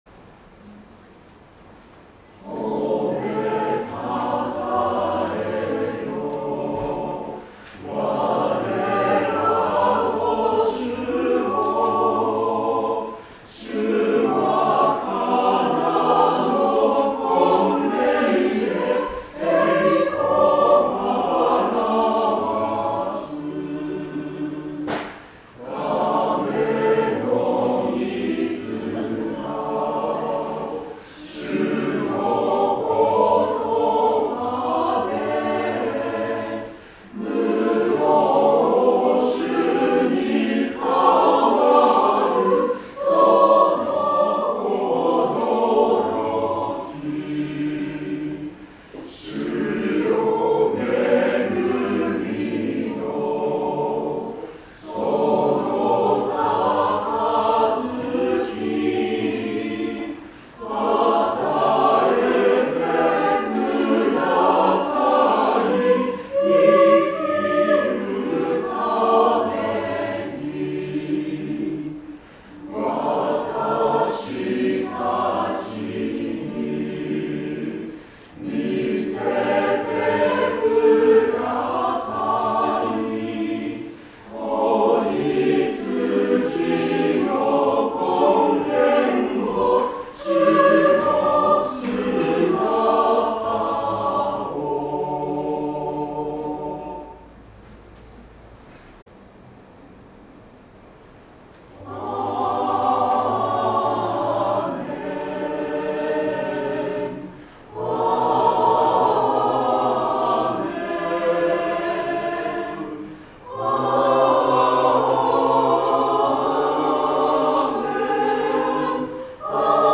聖歌隊奉唱・献花